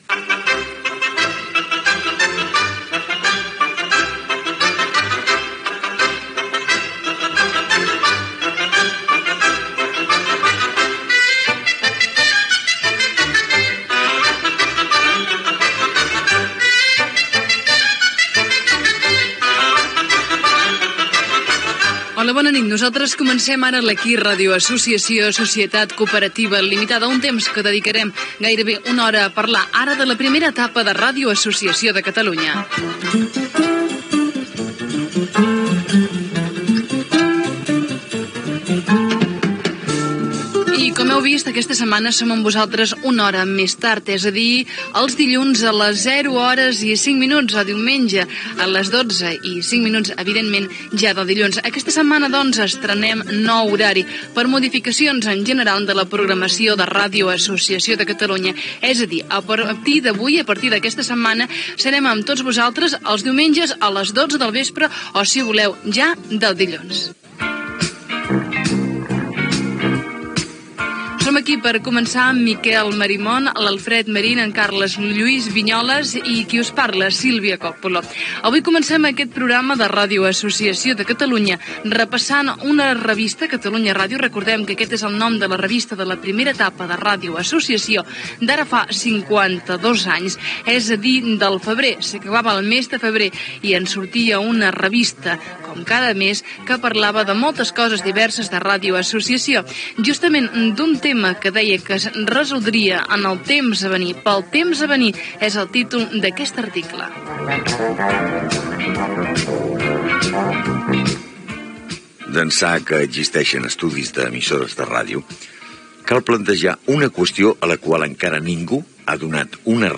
Sintonia, presentació, avís del canvi d'hora del programa, equip, lectura d'un article de la revista "Catalunya Ràdio" sobre els estudis de ràdio, tema musical, entrevista a
Gènere radiofònic Entreteniment